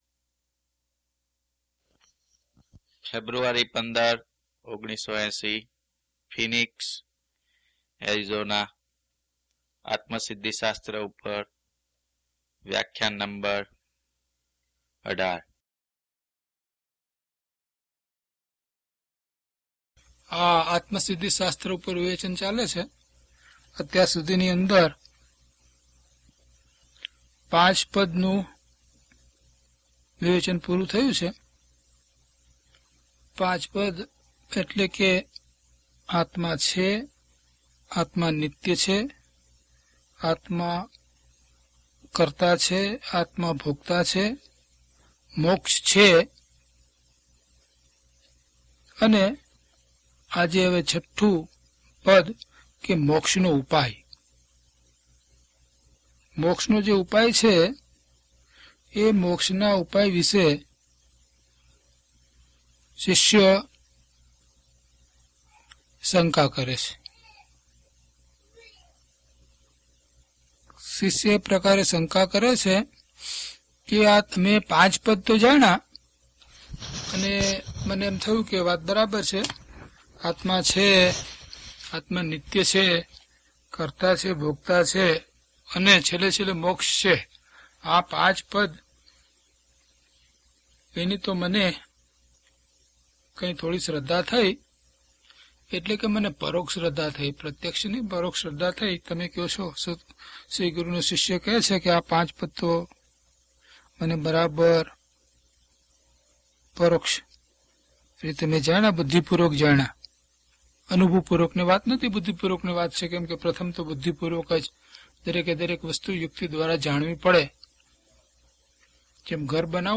DHP027 Atmasiddhi Vivechan 18 - Pravachan.mp3